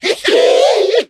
mech_mike_ulti_vo_02.ogg